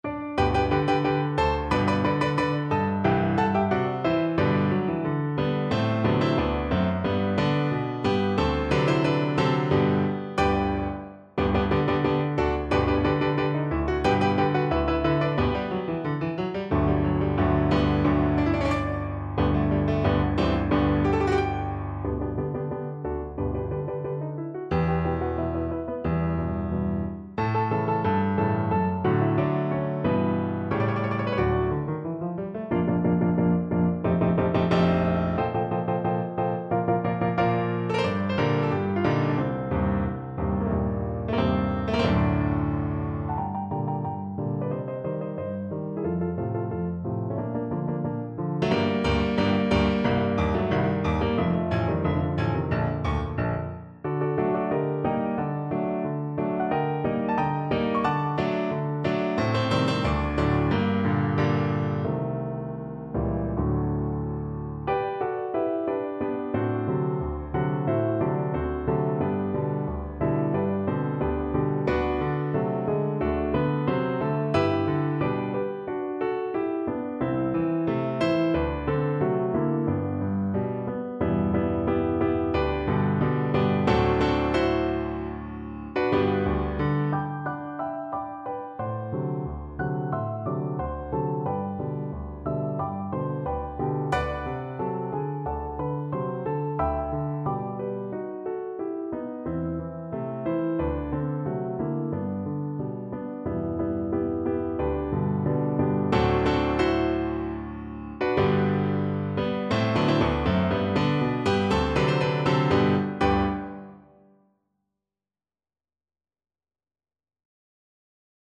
Viola
G major (Sounding Pitch) (View more G major Music for Viola )
Allegretto =90
2/4 (View more 2/4 Music)
Classical (View more Classical Viola Music)